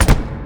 TOOL_Nailgun_01_mono edited.wav